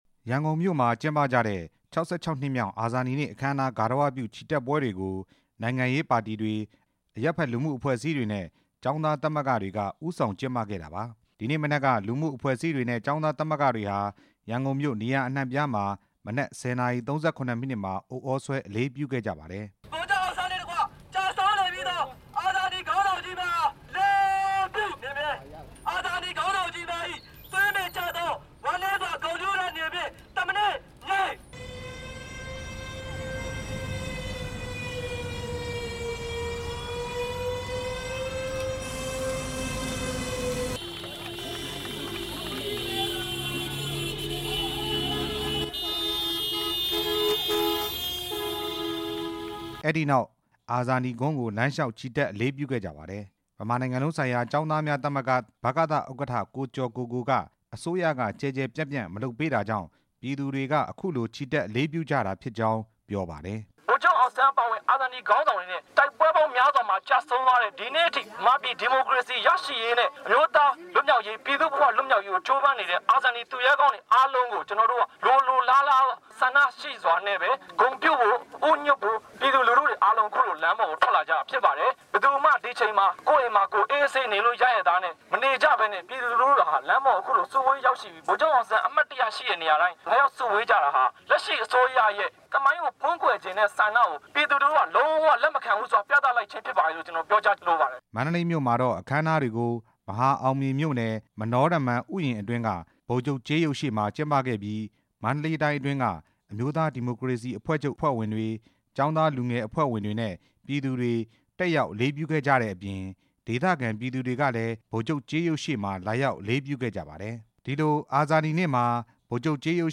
အာဇာနည်နေ့ အခမ်းအနားများ စုစည်းတင်ပြချက်